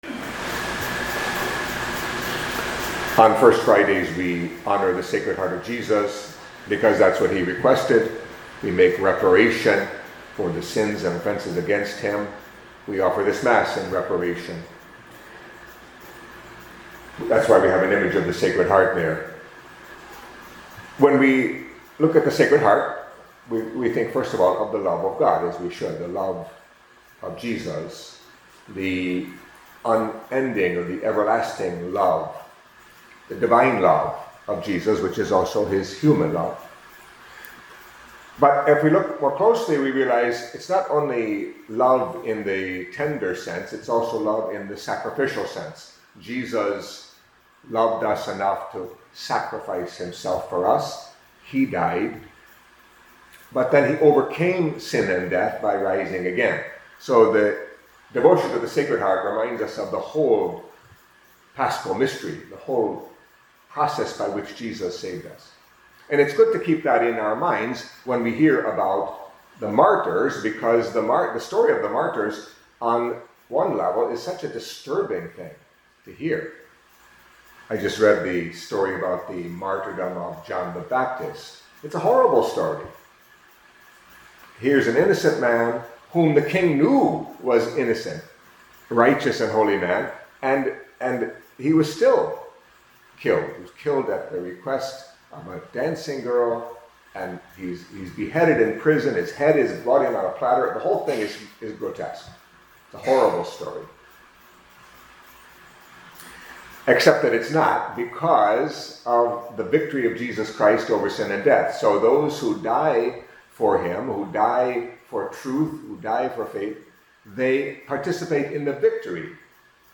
Catholic Mass homily for Friday of the Fourth Week in Ordinary Time